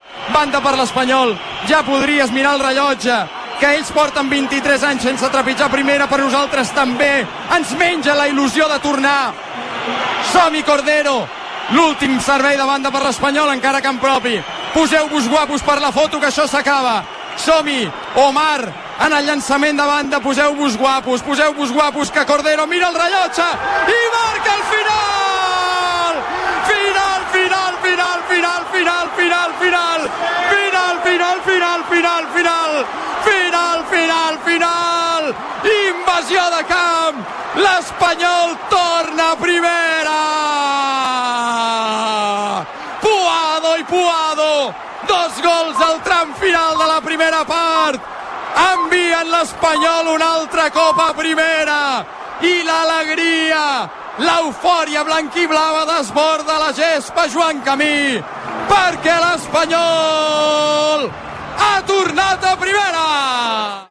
Moments finals de la transmissió del partit de futbol masculí entre el RCD Espanyol i el Real Oviedo. Xiulet de l'àrbitre i comentari de la pujada a Primera Divisió amb la descripció de l'estat emocional de l'aficionat perico.
Esportiu